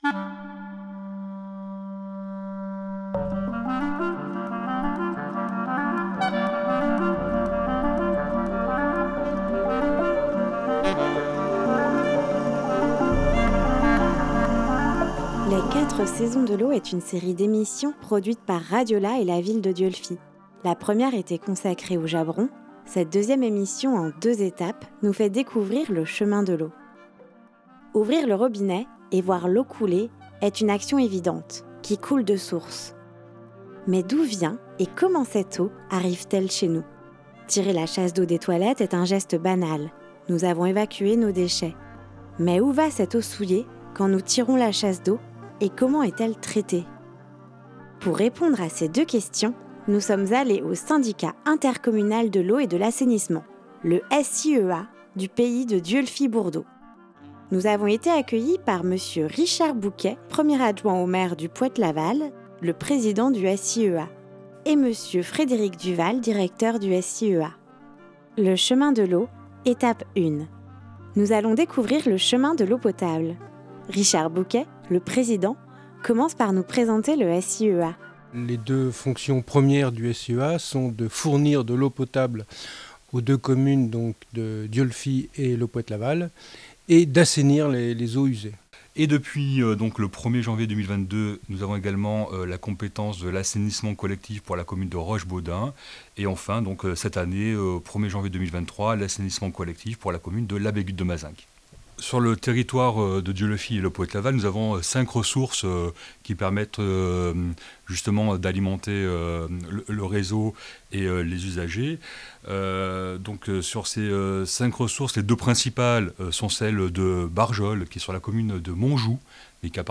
23 mars 2023 12:31 | Au fil de l'eau, emissions, reportage, territoire